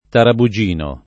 tarabugino [ tarabu J& no ]